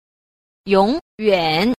7. 永遠 – yǒngyuǎn – vĩnh viễn